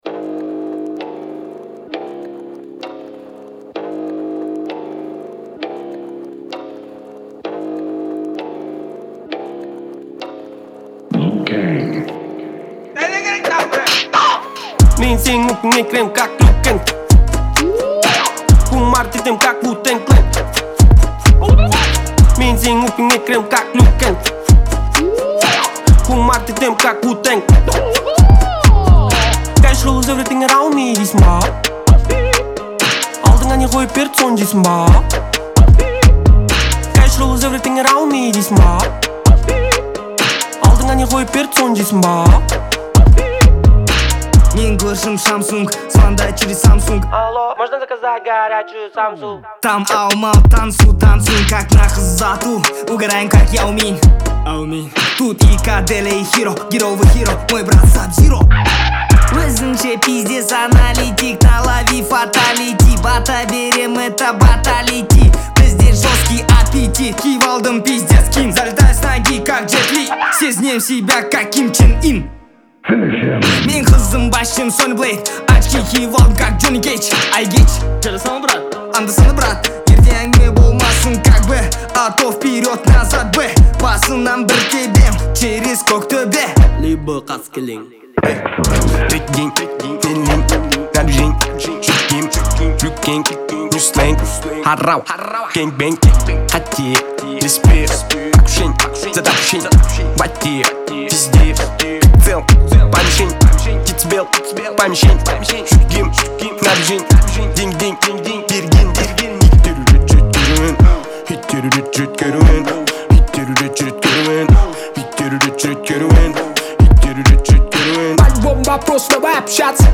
это яркий пример современного хип-хопа